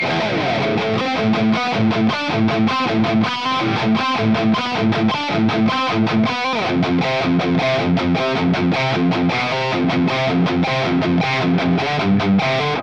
Metal Riff
RAW AUDIO CLIPS ONLY, NO POST-PROCESSING EFFECTS
Hi-Gain